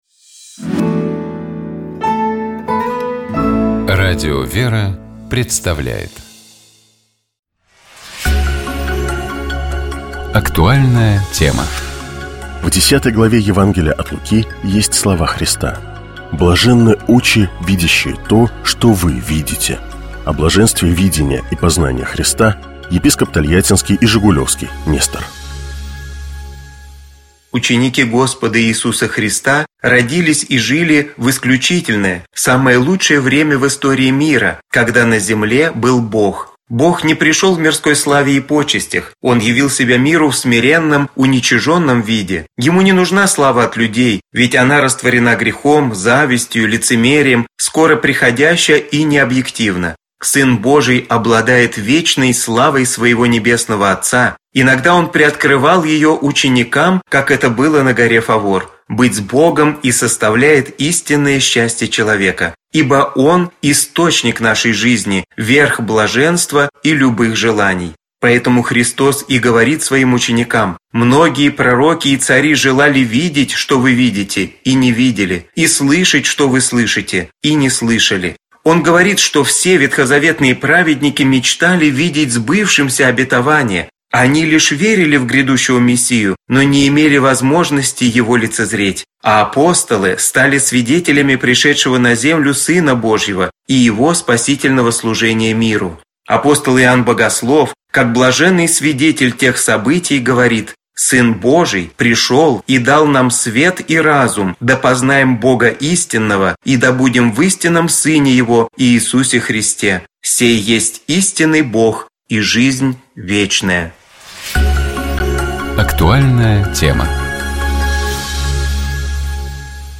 О блаженстве видения и познания Христа, — епископ Тольяттинский и Жигулёвский Нестор.